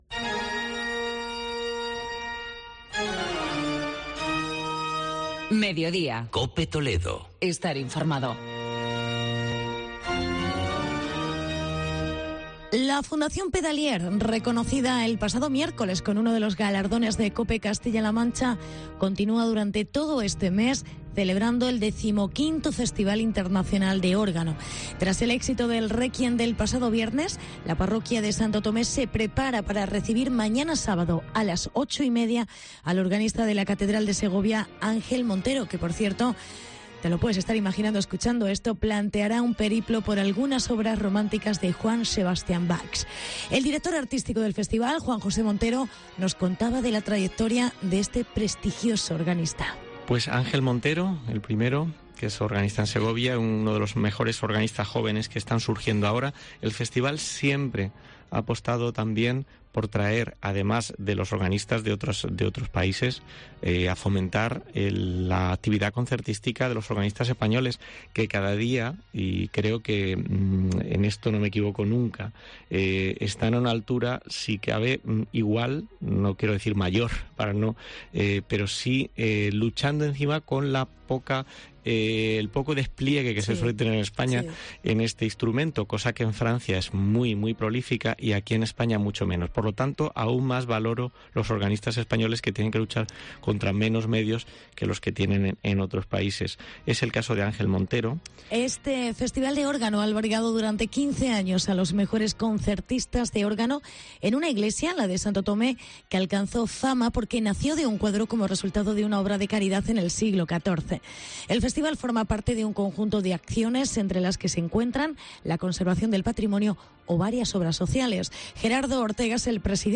XV Festival Internacional de Órgano de Toledo.